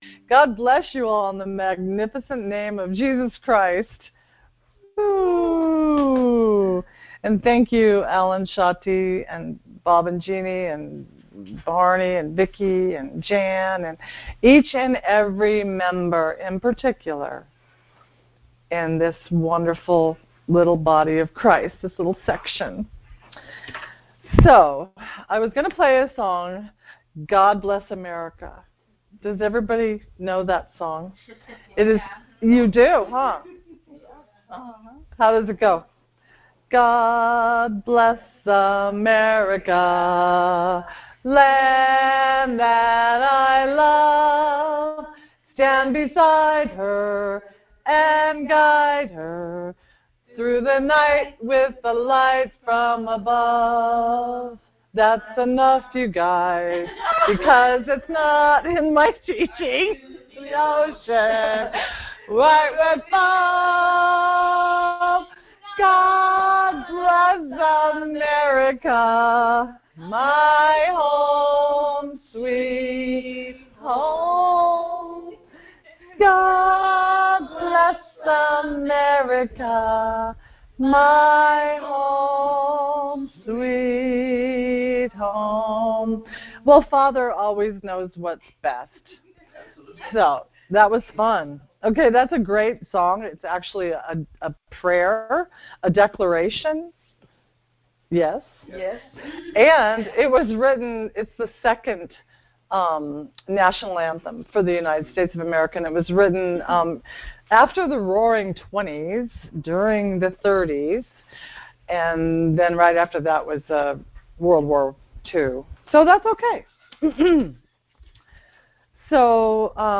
Details Series: Conference Call Fellowship Date: Monday, 18 September 2023 Hits: 375 Play the sermon Download Audio ( 11.55 MB ) Amen!